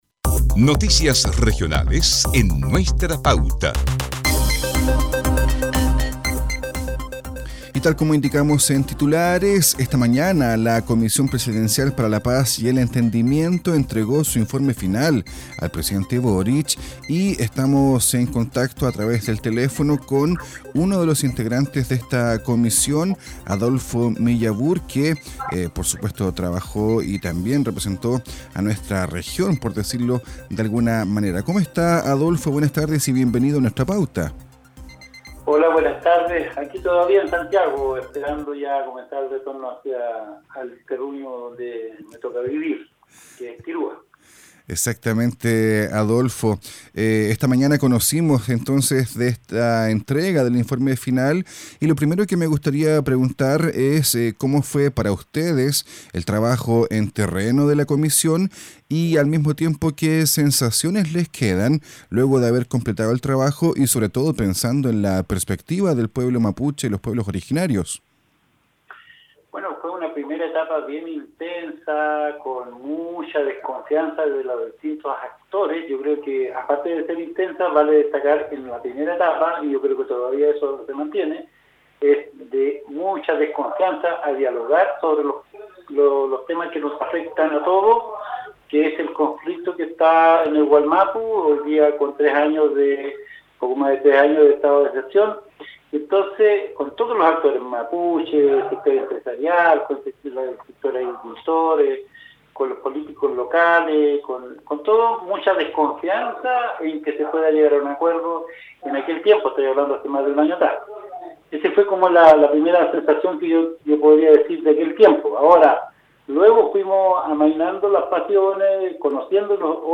En esta entrevista, el exalcalde de Tirúa repasa sus impresiones sobre la propuesta construida, los dolores de las víctimas y sus expectativas sobre los pasos que siguen.